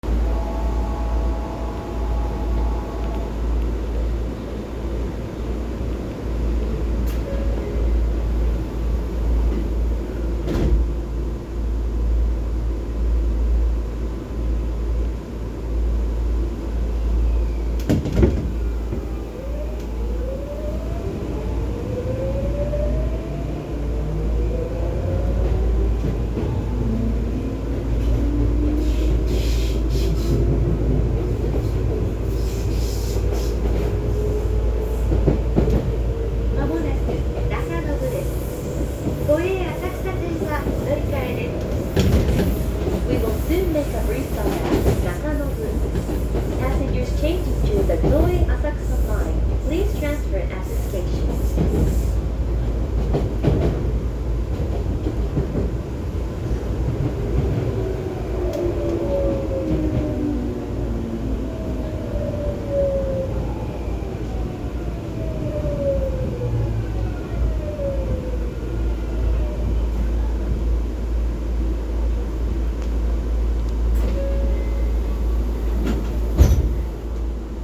・9000系走行音
【大井町線】荏原町→中延
日立の初期のVVVFインバータ音。9000系は2024年現在走行機器更新を受けておらず、この初期の初期のインバータ音が未だに聞ける貴重な存在です。